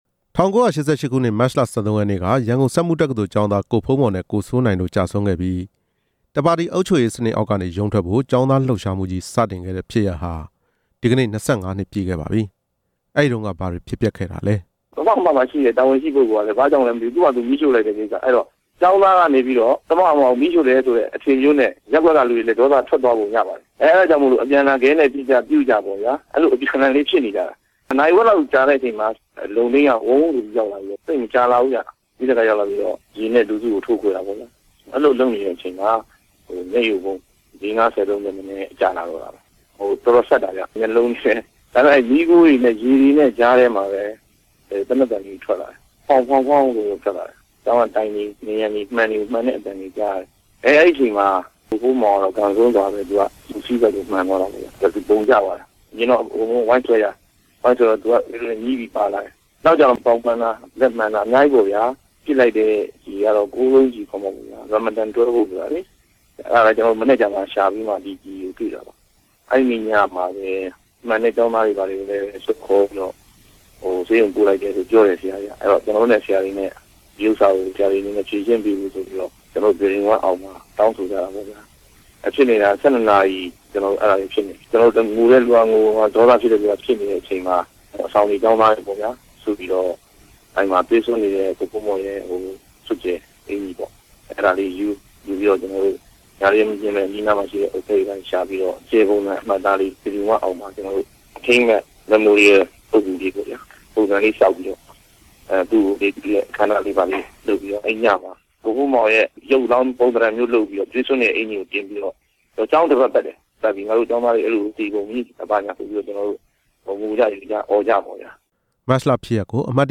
၁၉၈၈ ခုနှစ် မတ်လ ၁၃ ရက်နေ့က ရန်ကုန်စက်မှုတက္ကသိုလ်ကျောင်းသား ကိုဖုန်းမော်နဲ့ ကိုစိုးနိုင်တို့ကျဆုံးခဲ့ပြီး တပါတီအုပ်ချုပ်ရေးစနစ်အောက်ကနေရုံးထွက်ဖို့ ကျောင်းသားလှုပ်ရှားမှုကြီး စတင်ခဲ့တဲ့ဖြစ်ရပ်ဟာ ဒီနေ့ ၂၅ နှစ်ပြည့်ခဲ့ပါပြီ။ ဒီဖြစ်ရပ်နဲ့ပတ်သက်လို့ ကိုယ်တွေ့ကြုံခဲ့ရတဲ့ ကျောင်းသားဟောင်းတချို့ရဲ့ စကားတွေကို